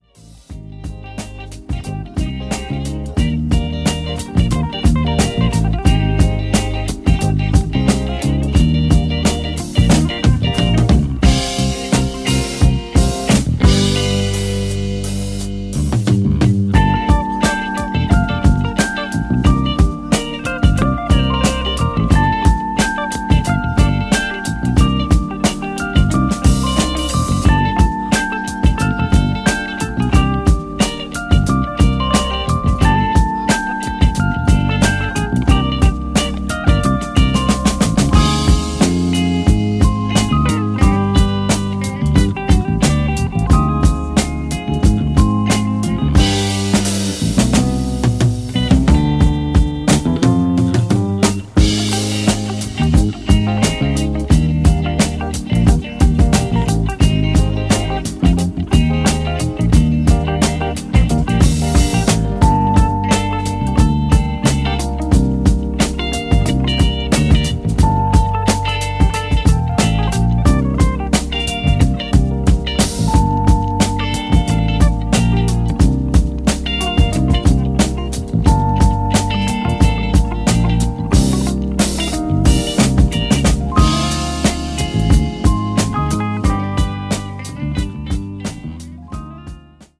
'77年にロンドンに録音されるもお蔵入りになっていたスタジオ・セッションが初の7"化。'
New Release Disco Classics Soul / Funk